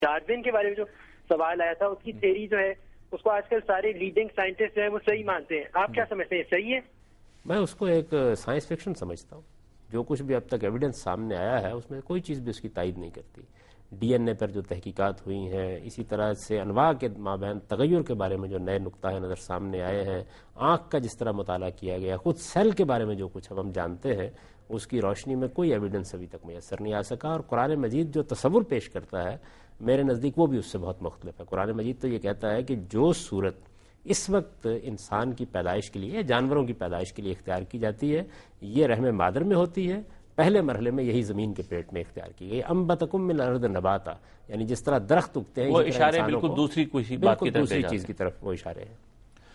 Category: TV Programs / Dunya News / Deen-o-Daanish / Questions_Answers /